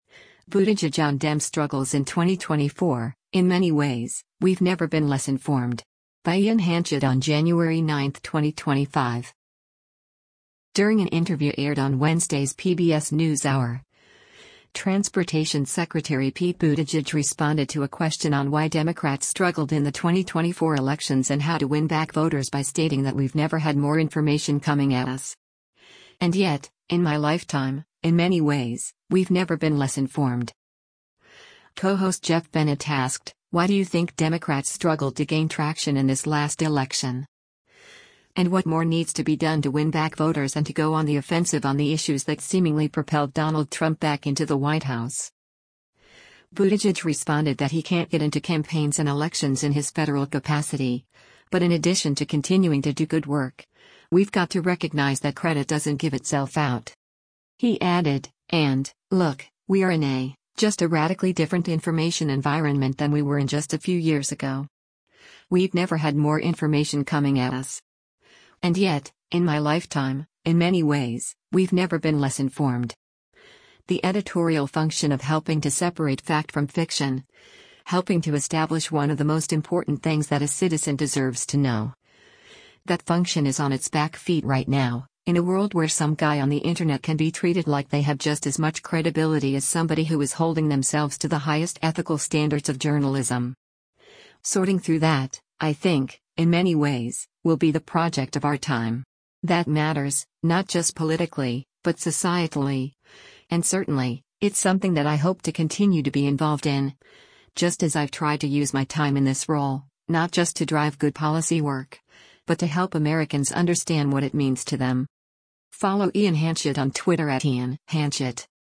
During an interview aired on Wednesday’s “PBS NewsHour,” Transportation Secretary Pete Buttigieg responded to a question on why Democrats struggled in the 2024 elections and how to win back voters by stating that “We’ve never had more information coming at us. And yet, in my lifetime, in many ways, we’ve never been less informed.”